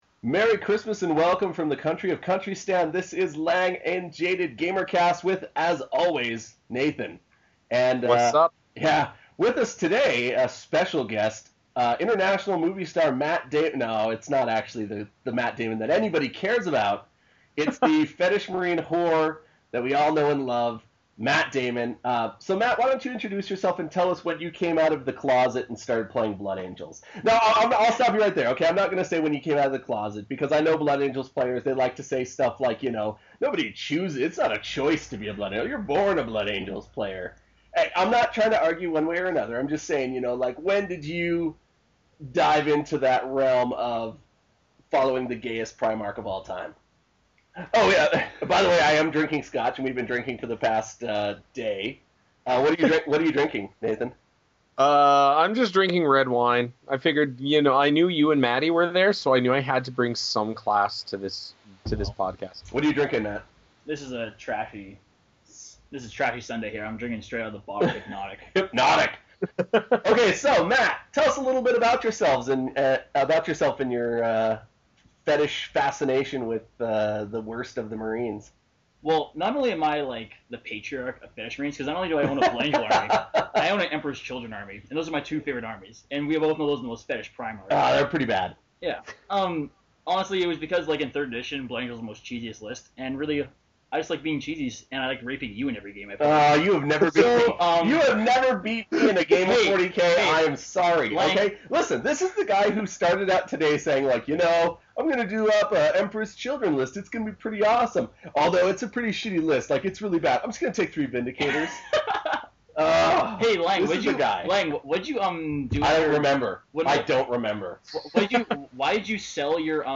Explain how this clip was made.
Apologies for the sound distortion as it seemed the mic was turned up way too high.